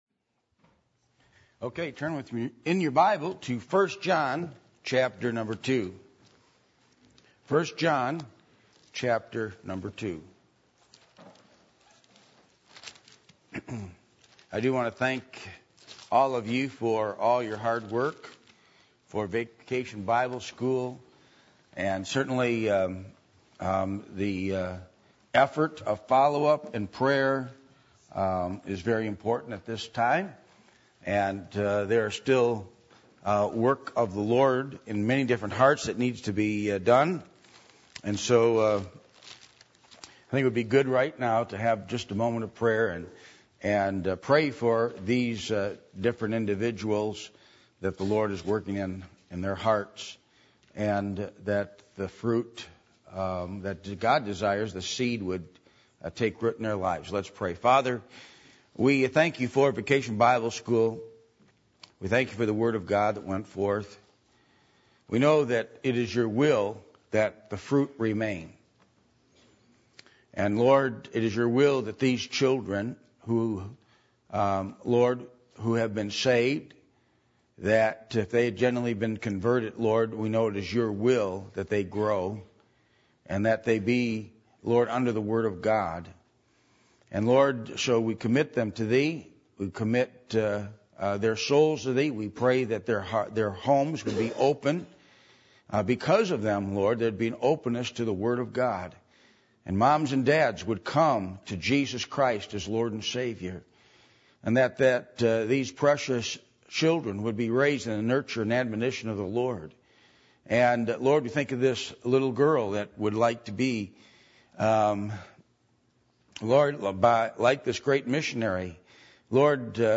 1 John 2:15-17 Service Type: Sunday Evening %todo_render% « Christian Home Series